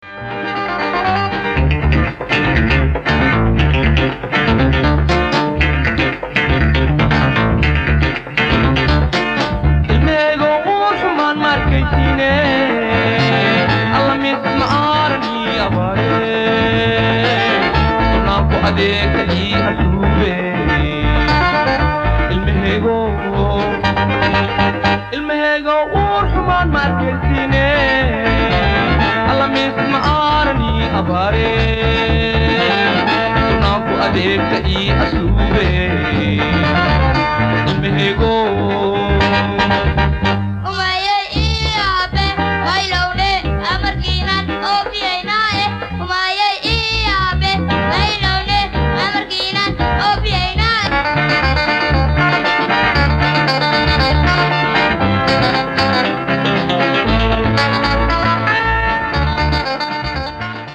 Digitized from cassettes